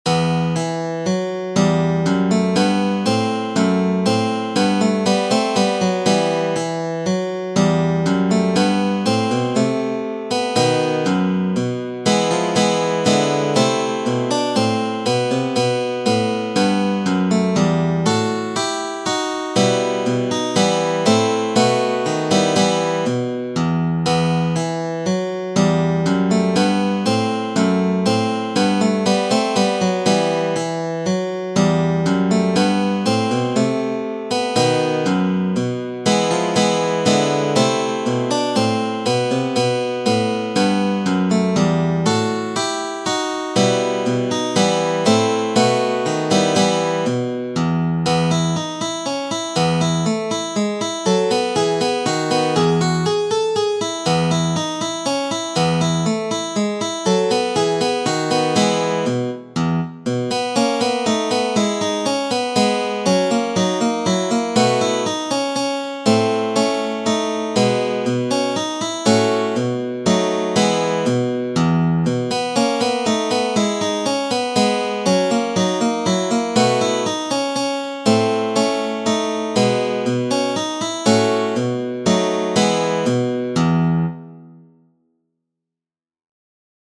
MINUETTO